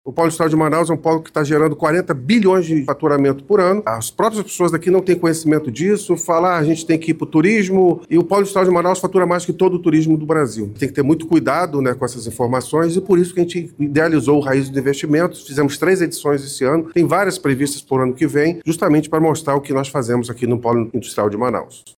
O secretário executivo da Sedecti, Gustavo Igrejas, ressaltou que o encontro, teve como principal objetivo, levar informações da Zona Franca de Manaus – ZFM à população.